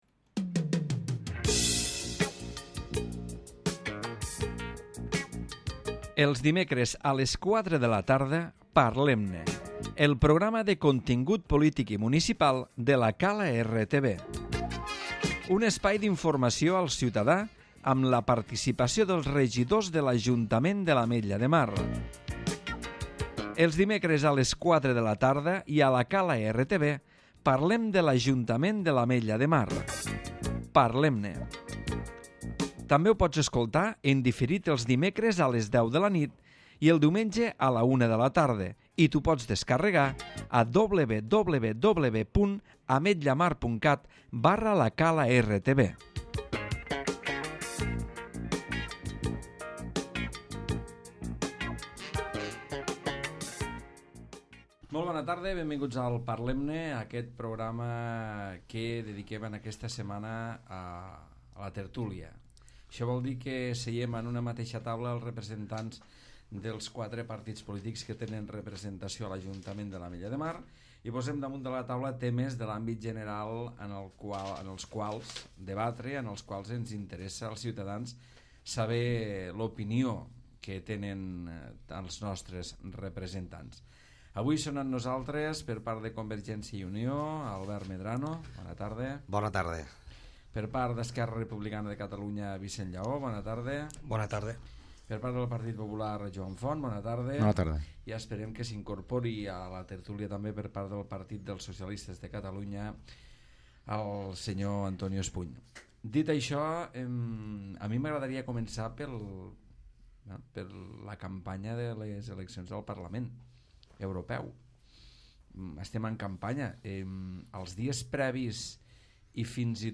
Representants dels grups municipals repassen l'actualitat general en format tertúlia. En aquesta ocasió amb la participació d'Albert Medrano per CiU, Vicent Llaó per ERC, Joan Font pel PP i Antonio Espuny pel PSC.